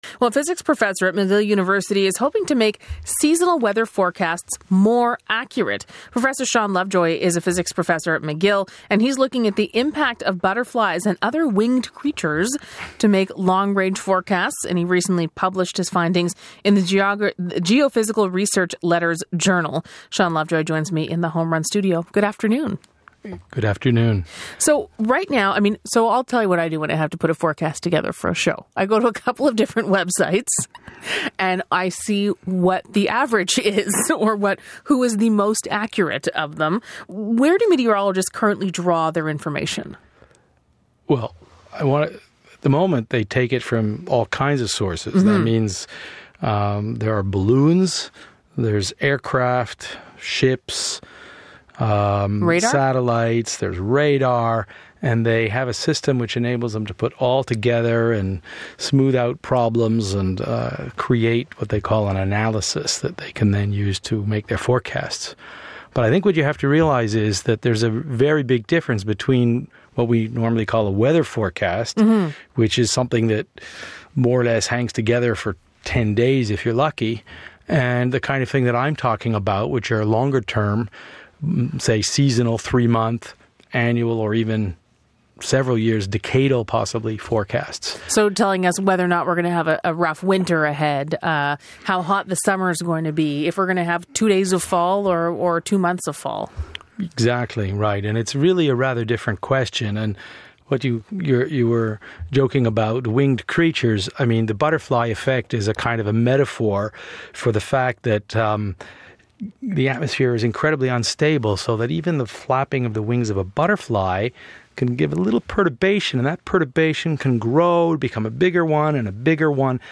Interview on harnessing butterflies